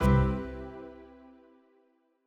Longhorn 8 - Background.wav